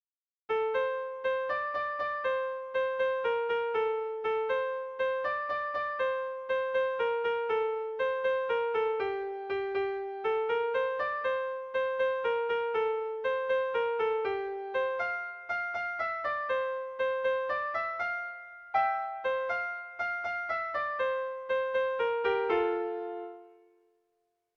Air de bertsos - Voir fiche   Pour savoir plus sur cette section
Sentimenduzkoa
AABDEF